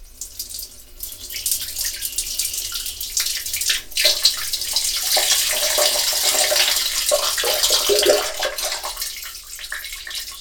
washing-hands-running-water
bath bubble burp click drain drip drop effect sound effect free sound royalty free Sound Effects